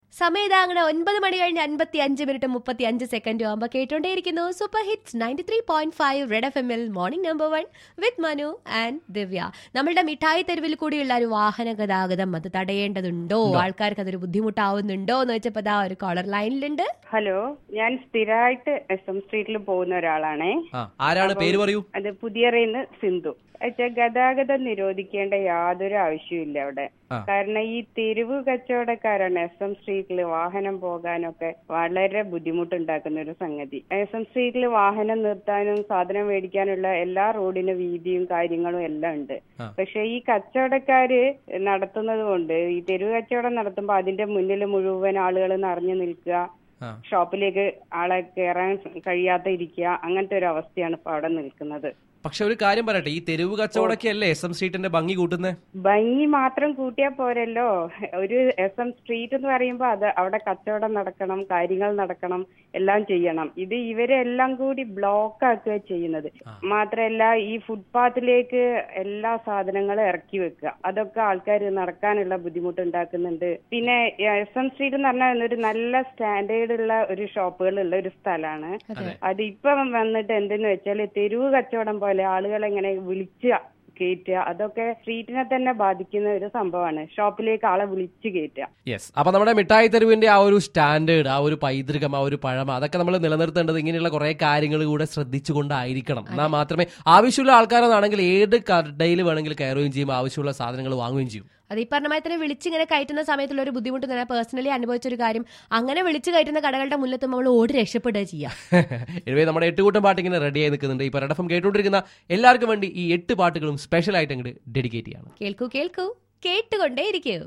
SM STREET, TRAFFIC , CALLER